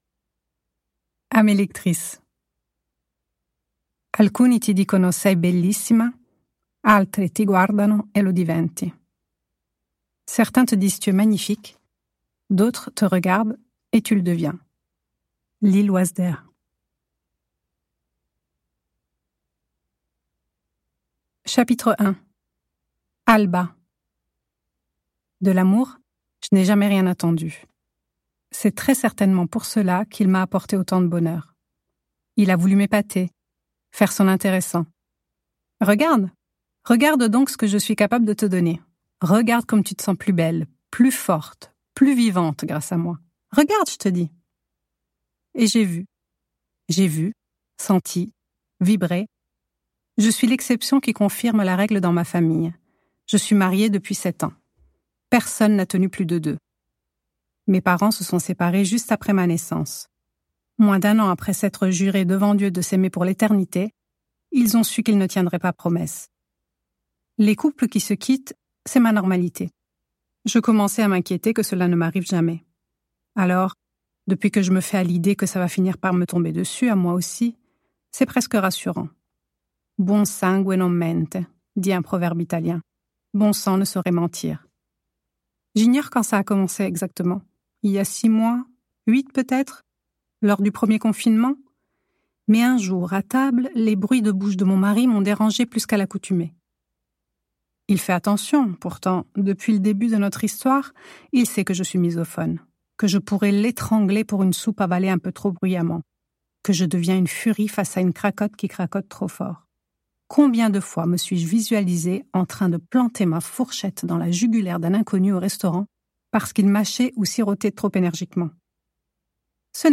Lu par l'autrice Juillet 2021. Deux couples d'amis se retrouvent à Procida, dans le sud de l'Italie.